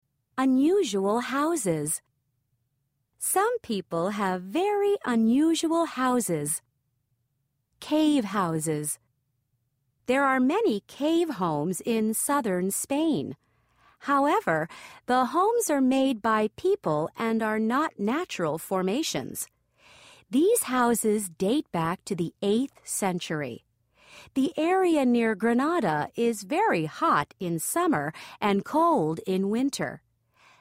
قطعه قرائيه جاهزه للصف الاول المتوسط ف2 الوحده السادسه mp3